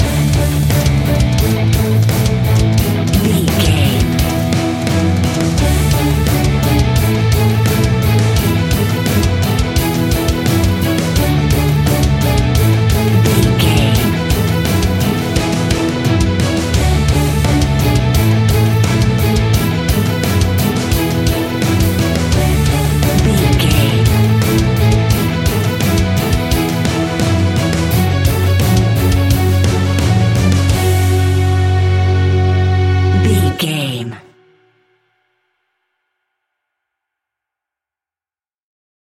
Epic / Action
Fast paced
Aeolian/Minor
F#
heavy metal
horror rock
rock instrumentals
Heavy Metal Guitars
Metal Drums
Heavy Bass Guitars